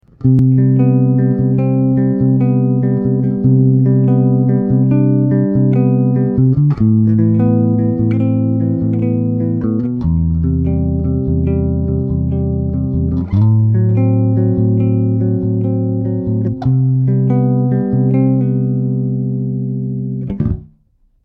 Adamovic Light Active Bass - Chords
A 5-string Adamovic Light active bass straight into the DI02.
DI02 - Adamovic Light Active Bass - Chords - BassTheWorld.mp3